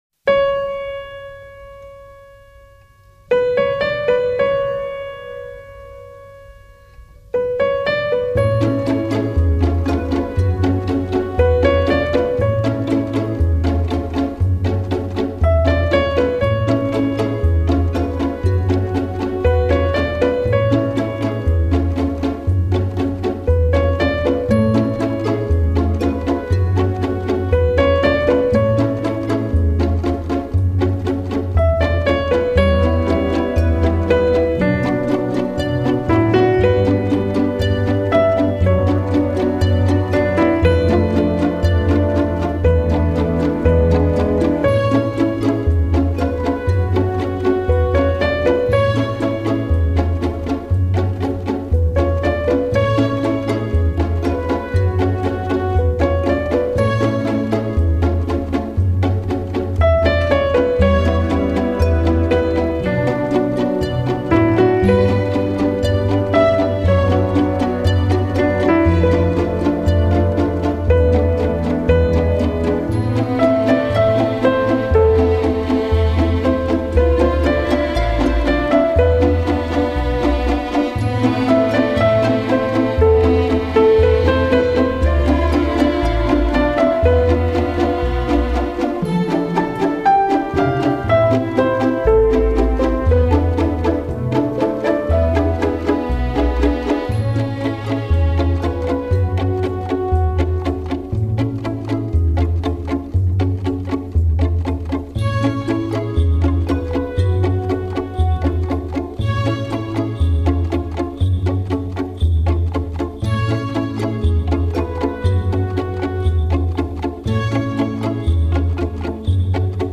铺陈出轻缓优美的旋律，流泄出法式的浪漫情调